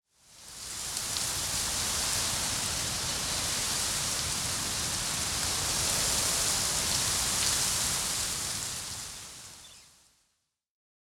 windtree_8.ogg